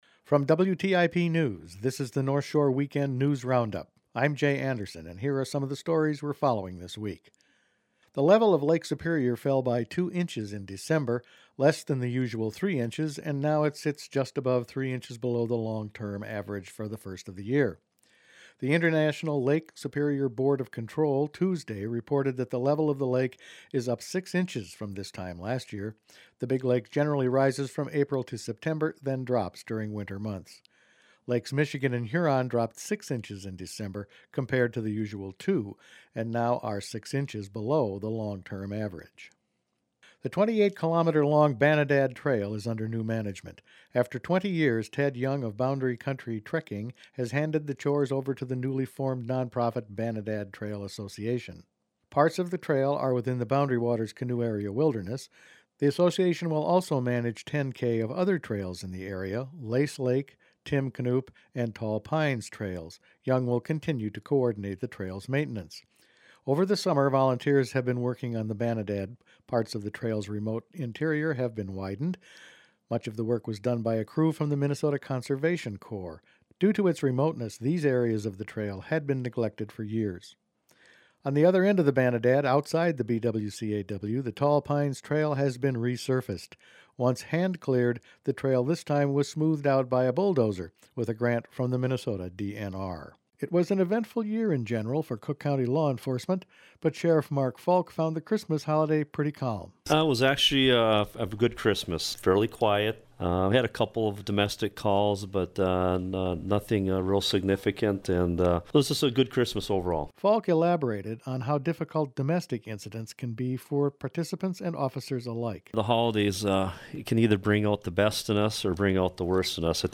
Each week the WTIP News Department provides a summary of the stories it has been following that week.